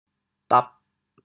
调: 滴 国际音标 [tap]